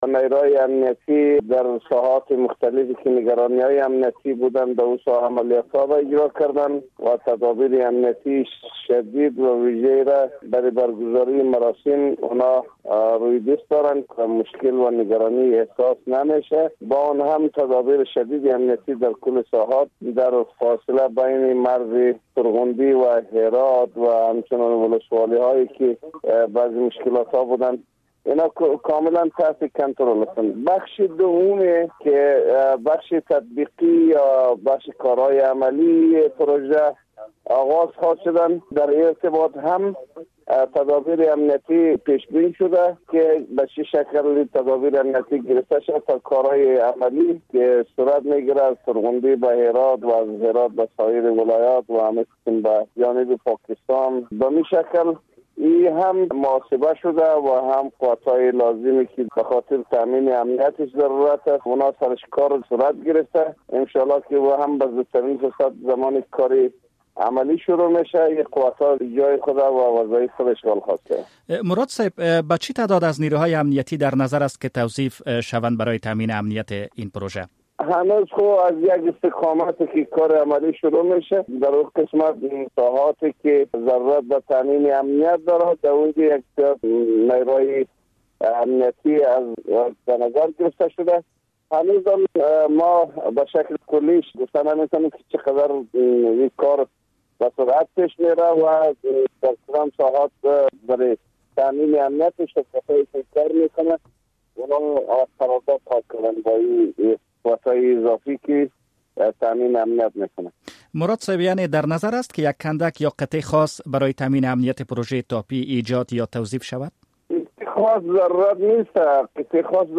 مصاحبه - صدا
این سخن را جنرال مراد علی مراد معین ارشد امنیتی وزارت داخله شب گذشته به رادیو آزادی گفت.